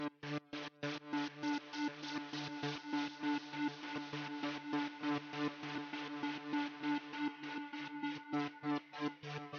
描述：气氛
标签： 100 bpm Ambient Loops Pad Loops 1.62 MB wav Key : A
声道立体声